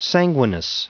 Prononciation du mot sanguineness en anglais (fichier audio)
Prononciation du mot : sanguineness